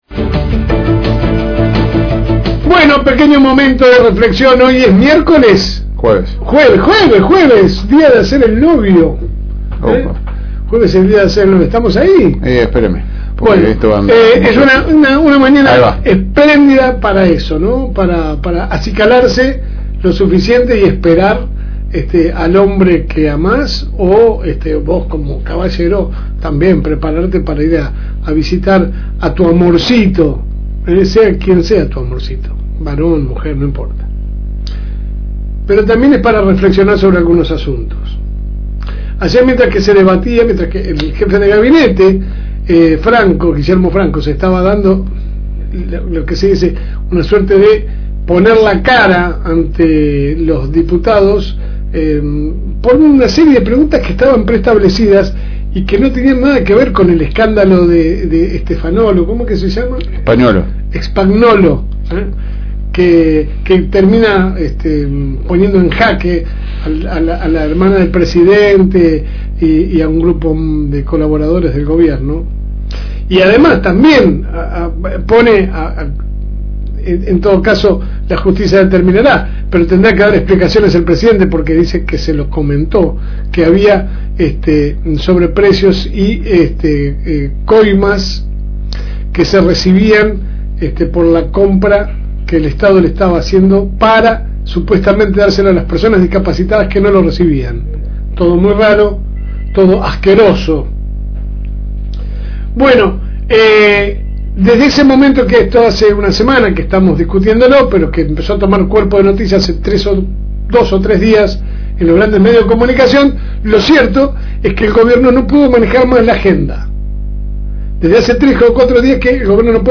Que sale por el aire de la Fm Reencuentro 102.9